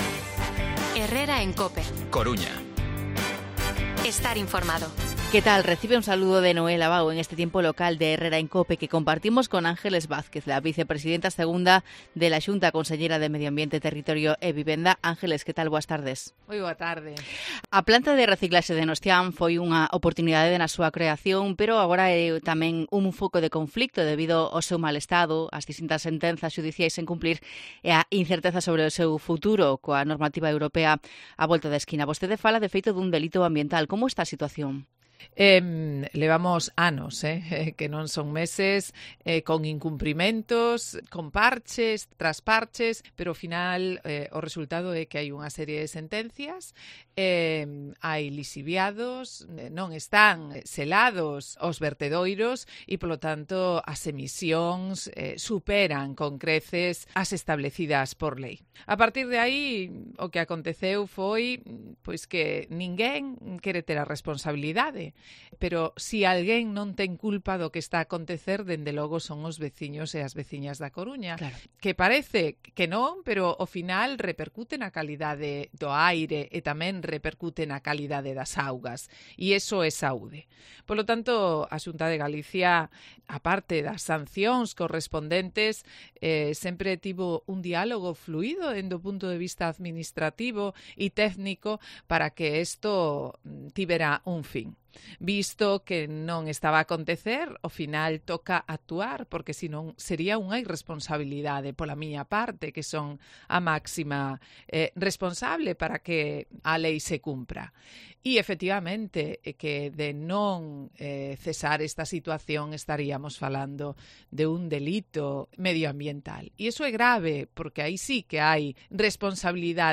AUDIO: Entrevista a la vicepresidenta segunda de la Xunta, Ángeles Vázquez